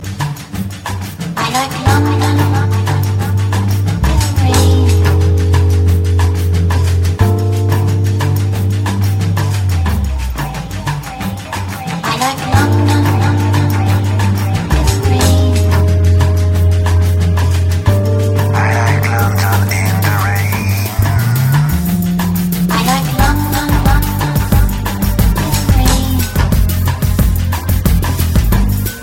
london-in-the-rain_23772.mp3